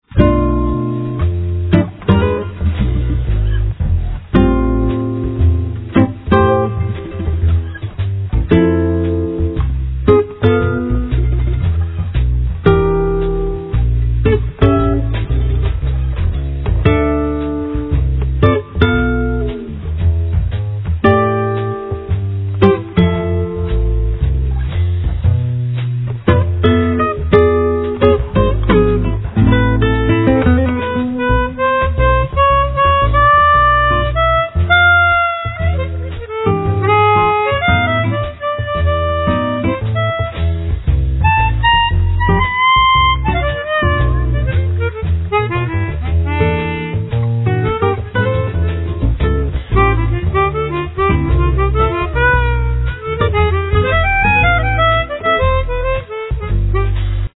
Carlos do Carmo   Guitar
Acoustic bass
Accordion
Drums
Harmonica